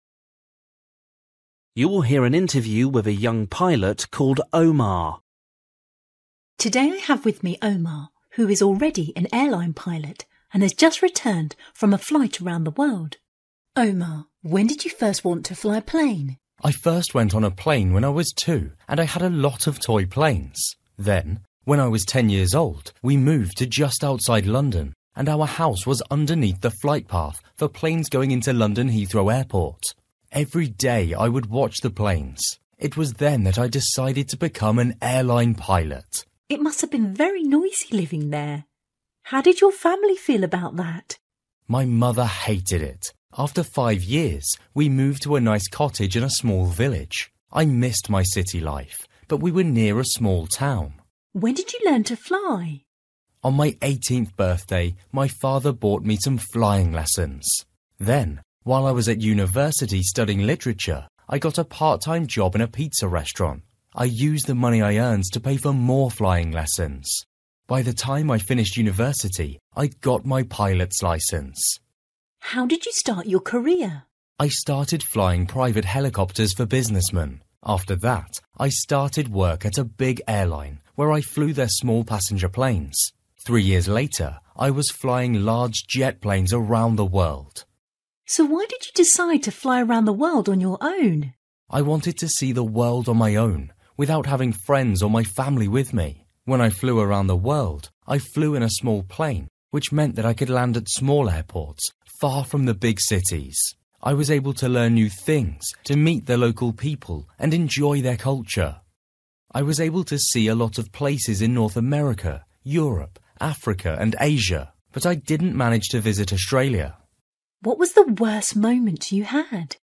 Listening: a young pilot